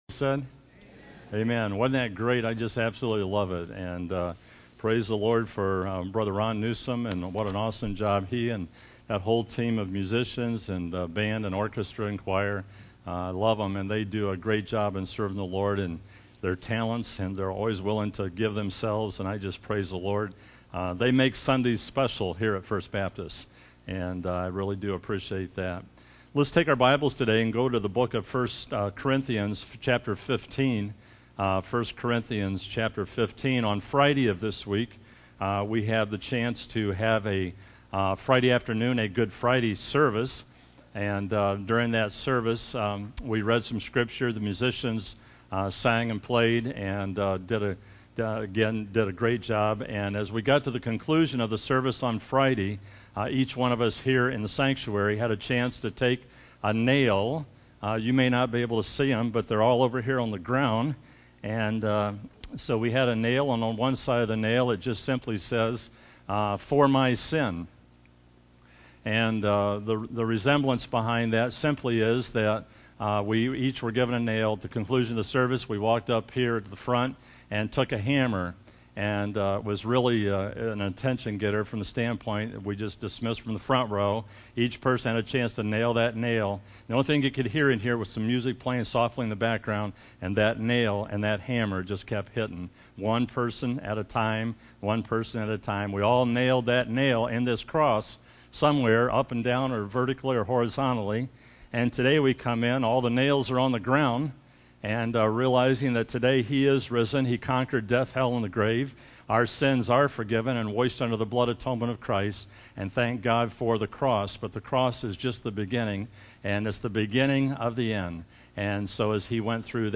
03-27-16 AM Easter Service